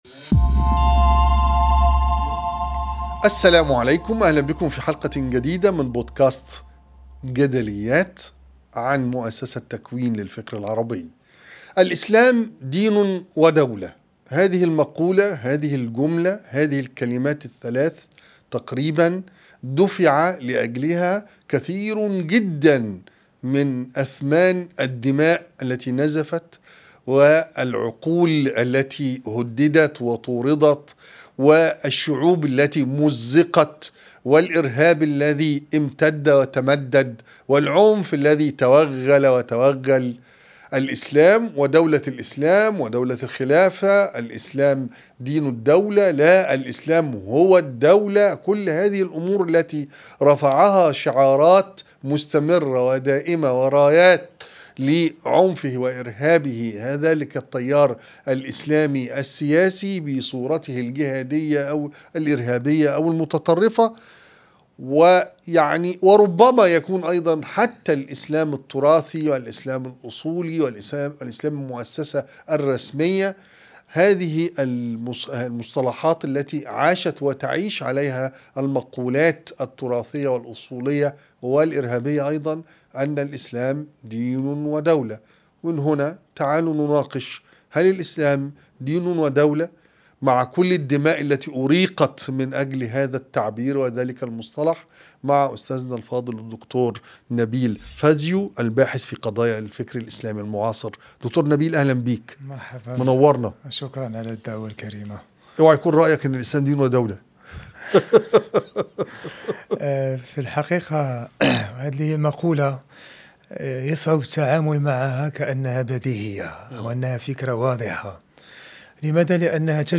بودكاست جدليات مع إبراهيم عيسى الموسم الرابع كيف تفهم التيارات الإسلامية مفهوم الدولة؟ حوار